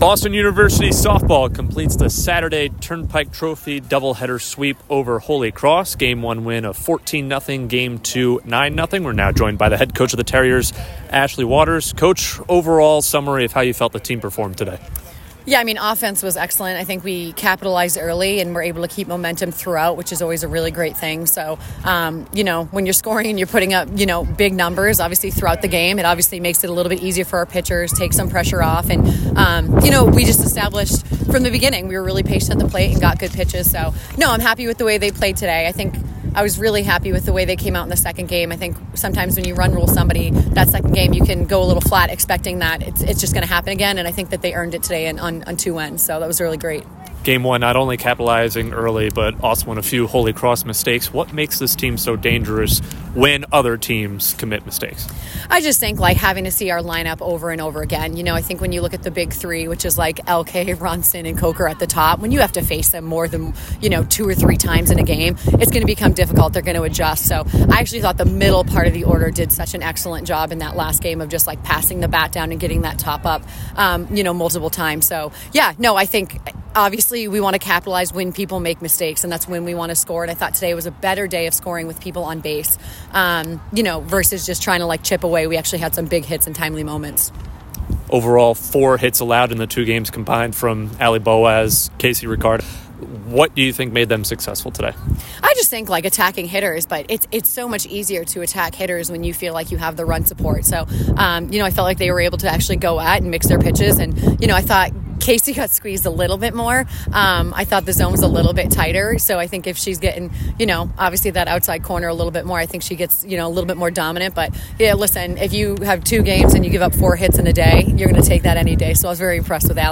Holy Cross Postgame Interview
Softball_HC_DH_Postgame.mp3